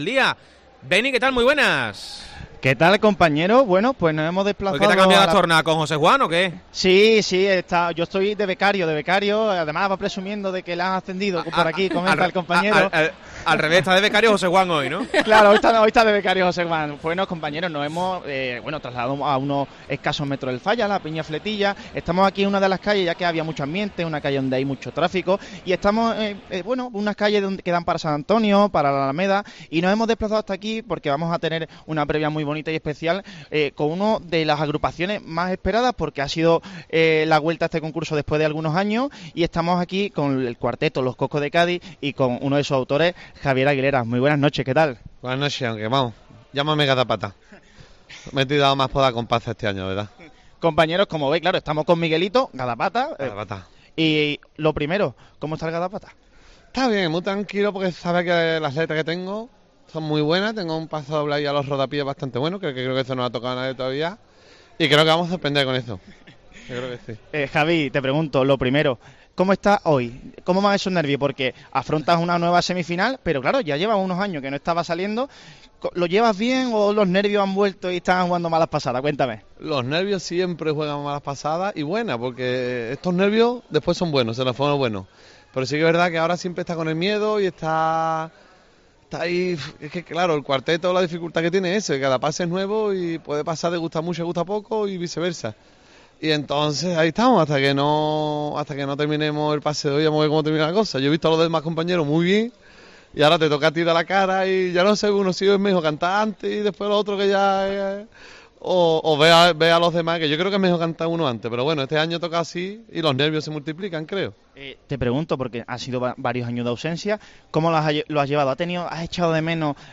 Carnaval